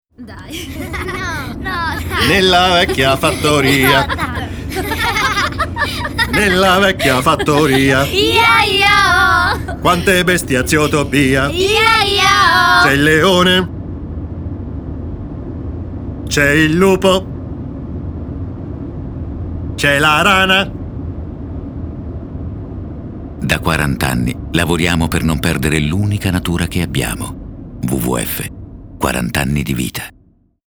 Spot radio “40 anni”